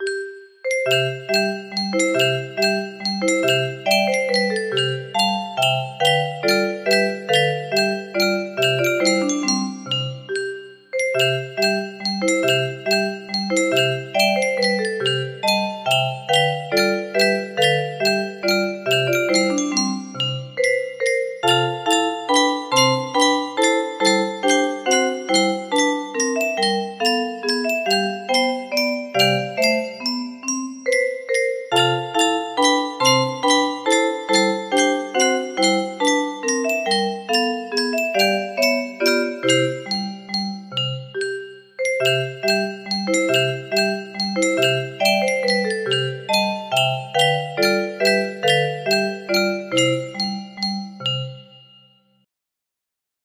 Anónimo - La Palomita music box melody
Aire nacional mexicano del estado de Coahuila